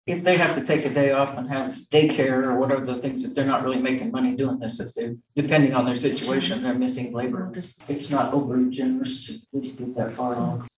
Commissioner Dee McKee says the increase should ease some financial burden to those called to jury duty.